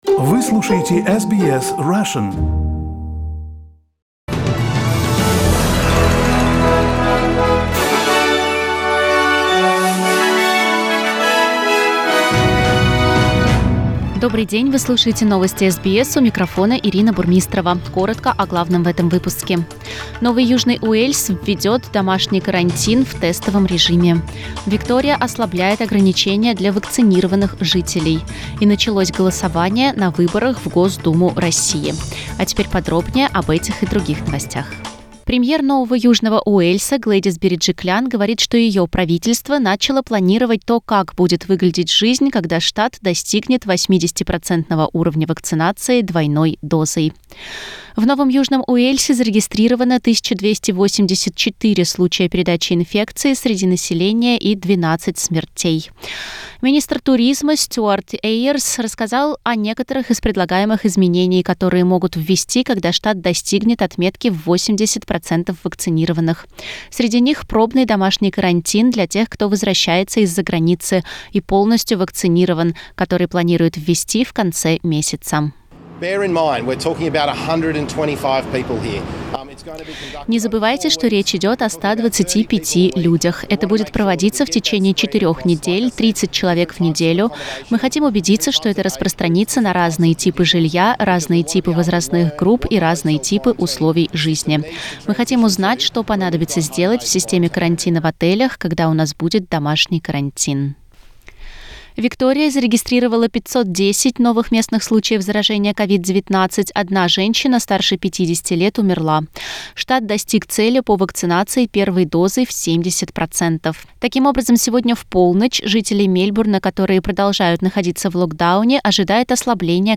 SBS news in Russian - 17.09